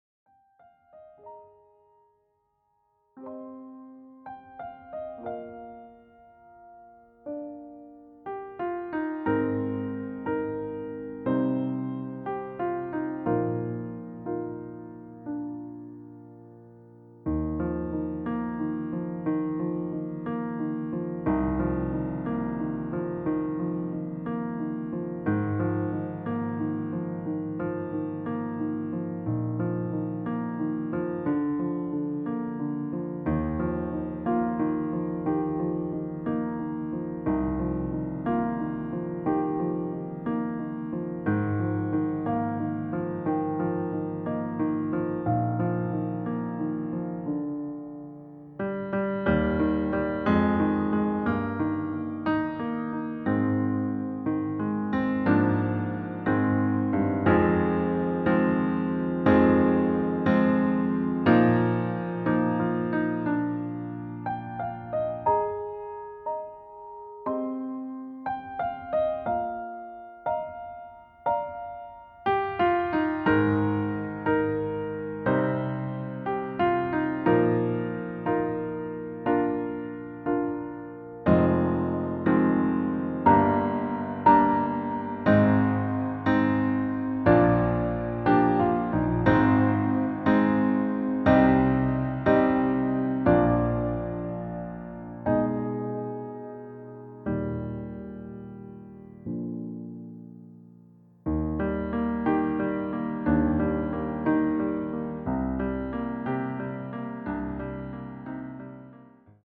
• Das Instrumental beinhaltet NICHT die Leadstimme
Klavier / Streicher